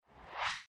ui_interface_254.wav